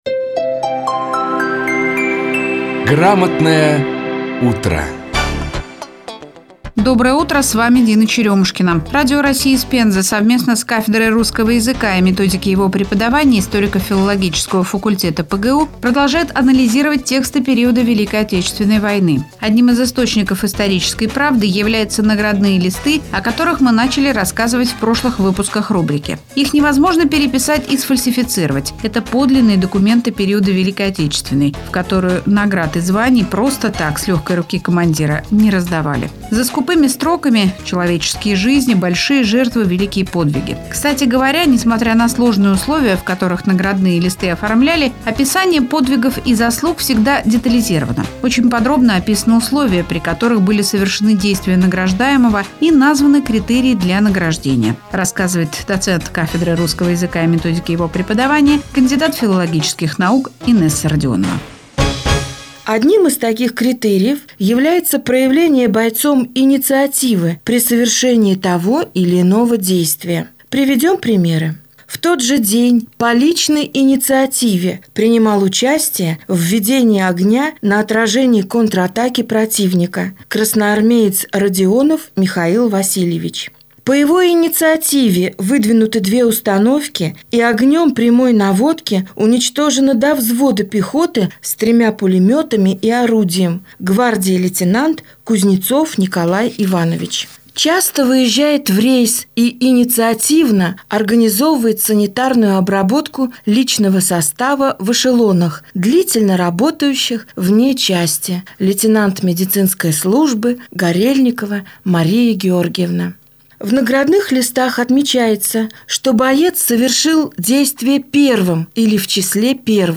Выступление
в эфире «Радио России из Пензы»